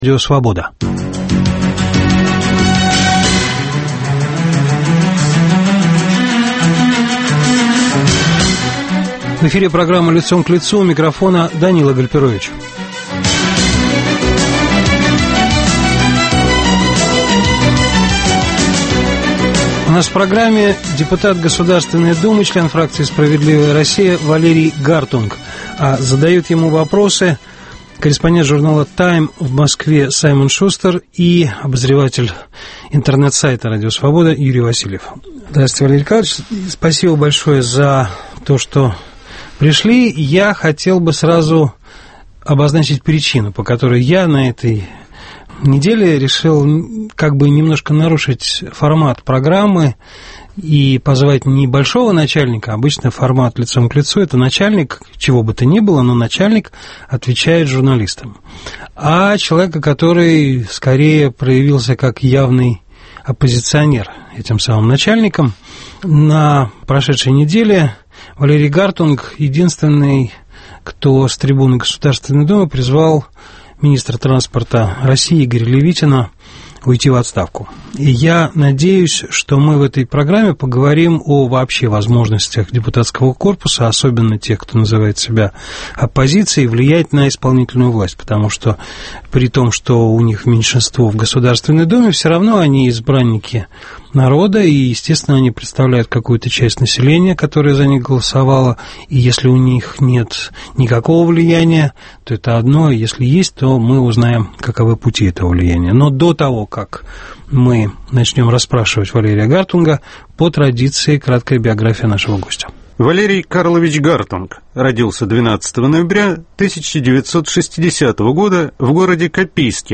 В программе - депутат Государственной думы, член фракции "Справедливая Россия" Валерий Гартунг.